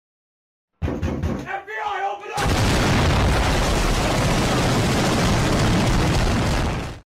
FBI Open Up With Explosion Sound Effect Free Download
FBI Open Up With Explosion